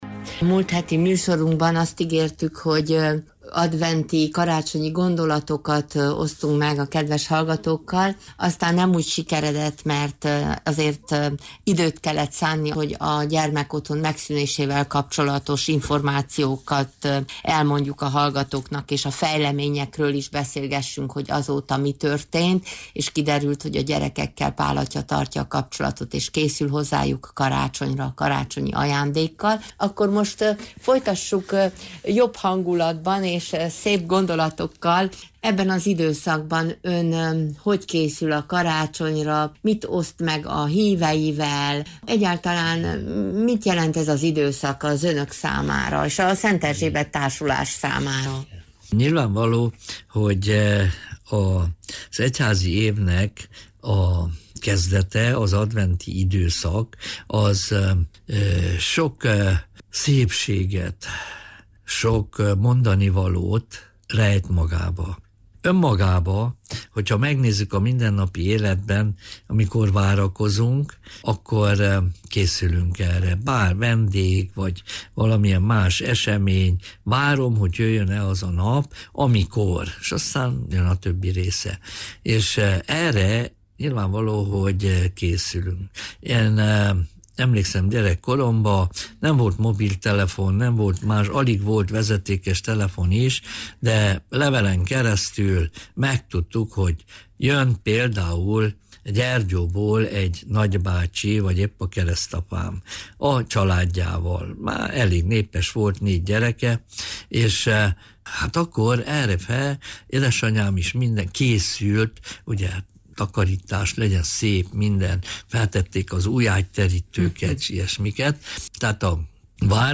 A múlt szombaton elhangzott beszélgetés itt hallgatható meg újra: